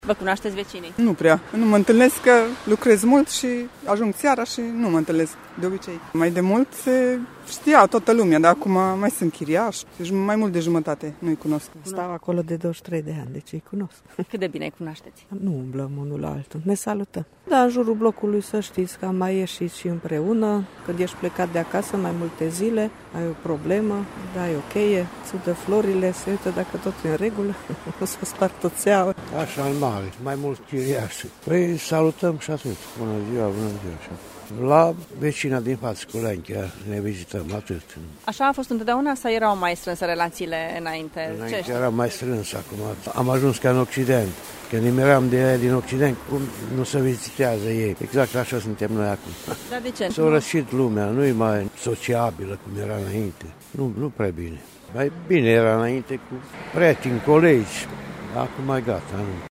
Târgumureșenii recunosc că nu se prea cunosc cu vecinii, deși fiecare are cel puțin o persoană din bloc de încredere în caz de nevoie: